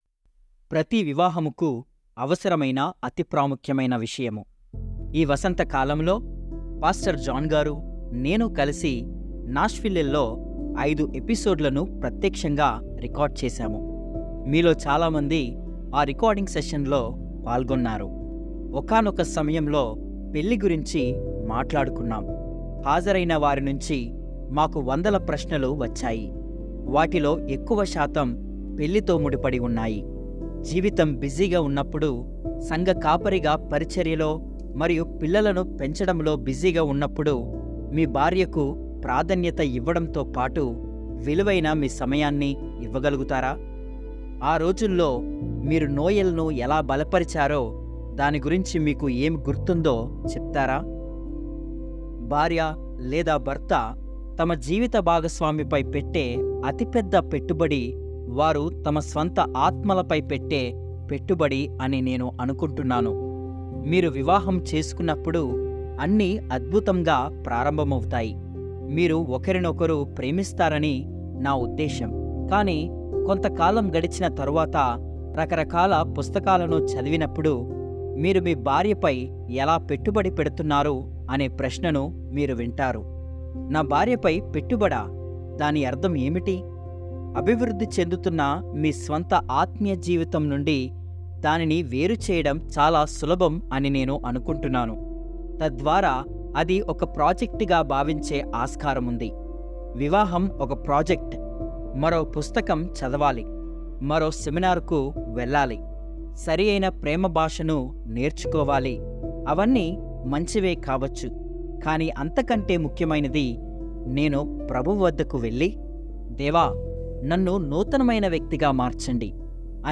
ఈ వసంతకాలంలో, పాస్టర్ జాన్ గారు, నేను కలిసి నాష్విల్లేలో ఐదు ఎపిసోడ్లను ప్రత్యక్షంగా రికార్డ్ చేసాము. మీలో చాలా మంది ఆరికార్డింగ్ సెషన్‌లో పాల్గొన్నారు.